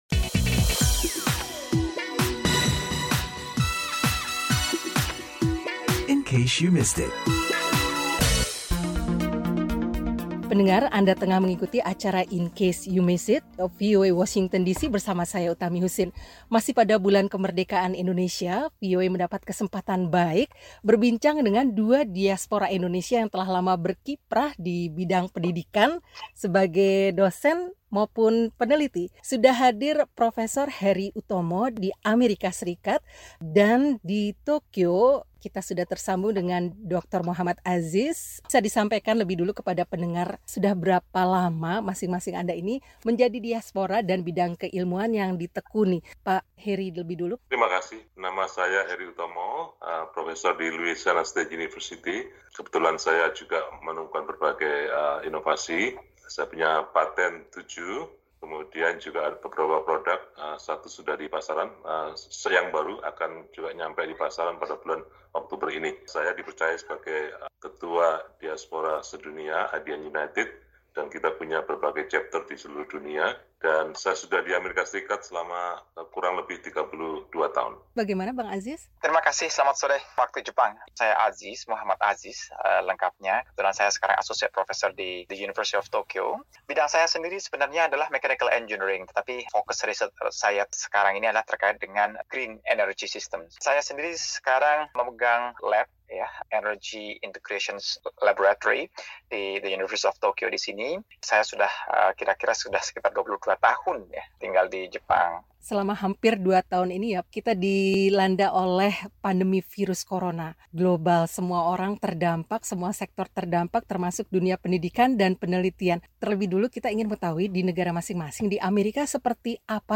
berbincang dengan dua peneliti senior